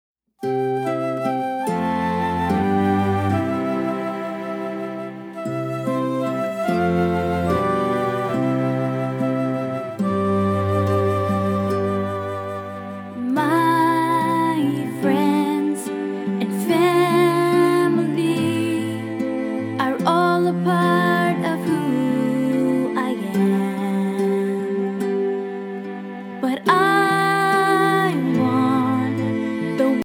Fun, sing-a-long music for home or classroom.